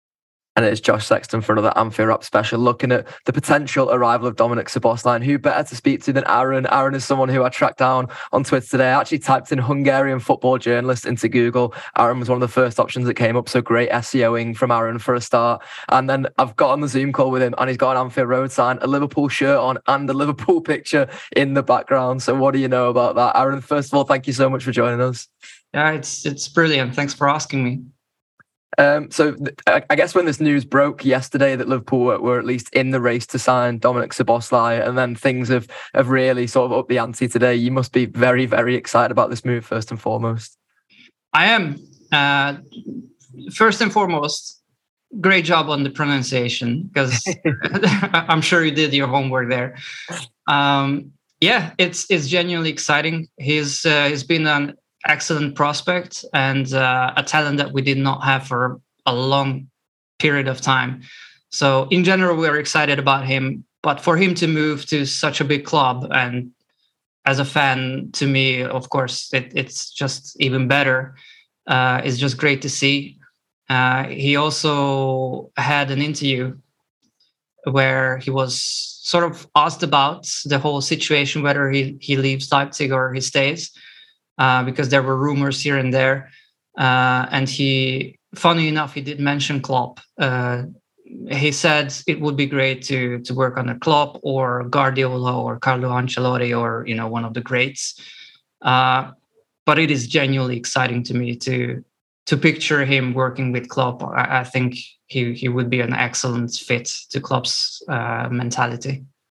Hungarian football journalist, gives the Hungary perspective on Liverpool target